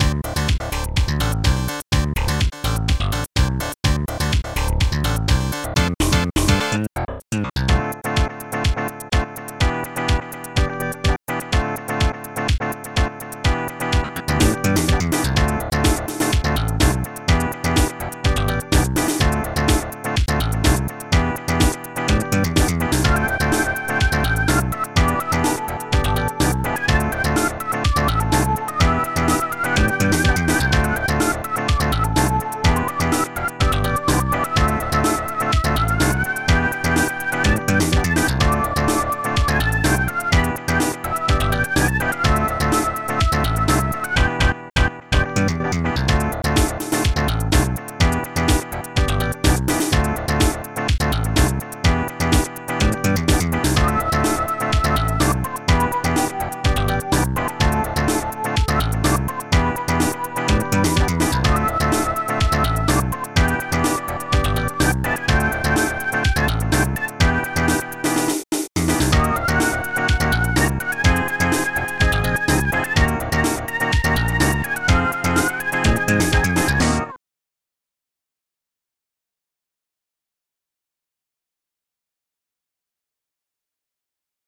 st-01:snare
st-01:ez clav2
st-02:jazz-organ
ST-34:xhihat2
ST-02:ElecBass1 1